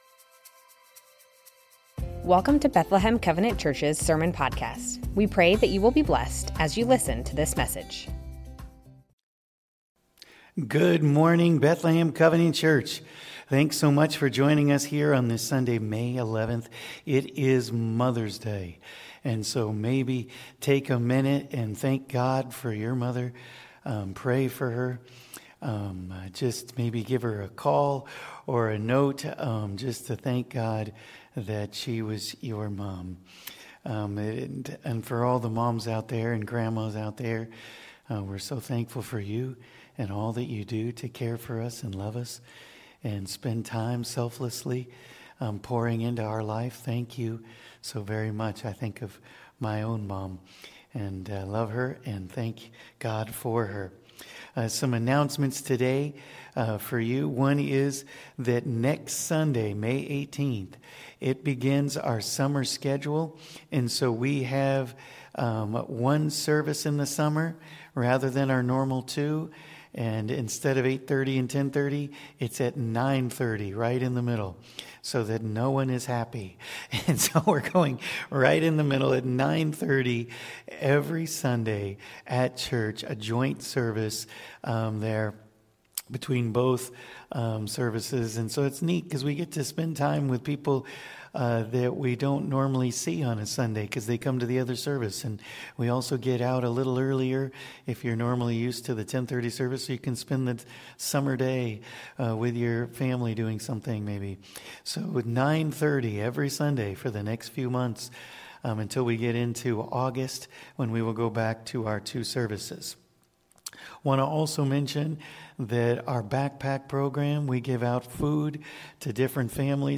Bethlehem Covenant Church Sermons Matthew 12:1-21 - Don't Miss the Point May 11 2025 | 00:35:03 1x 00:00 / 00:35:03 Subscribe Share Spotify RSS Feed Share Link Embed